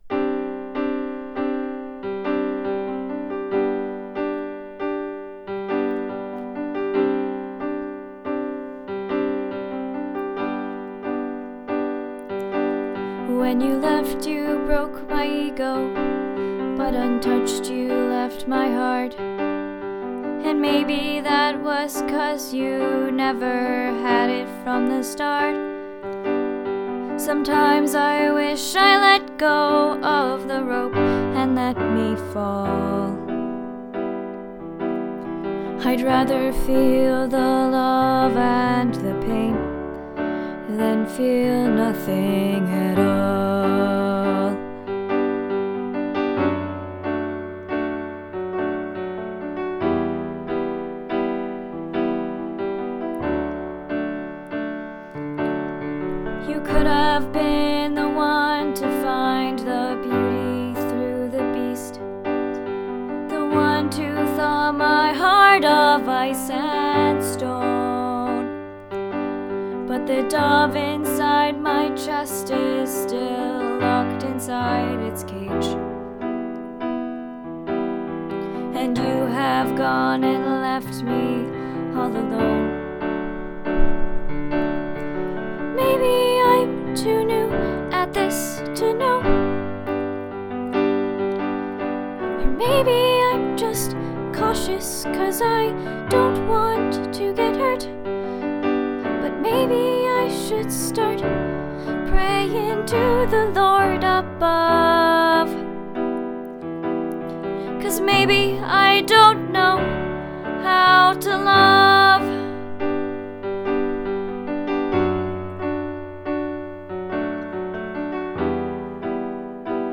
Nothing at All is sung by a young woman named Mandy as she contemplates her first—and lost—romantic relationship. She writes an imaginary letter to Noah, the object of her love, as a means of reflecting on her capacity to love another.